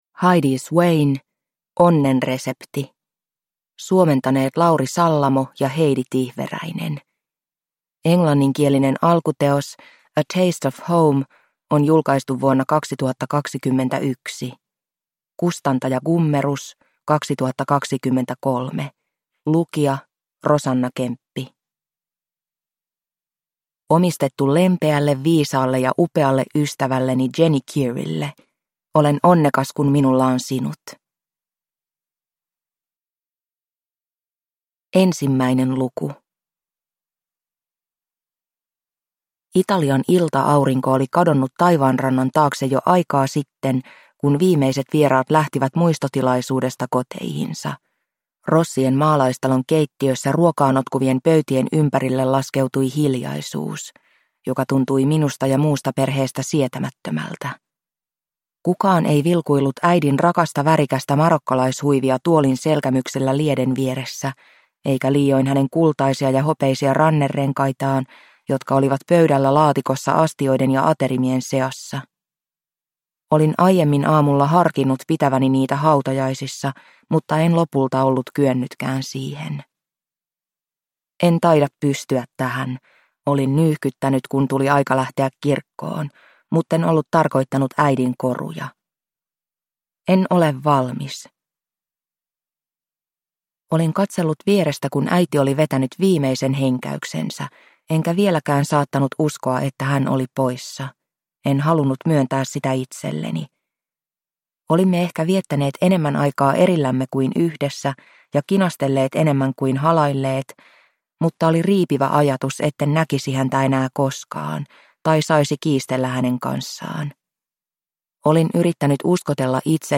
Onnen resepti – Ljudbok – Laddas ner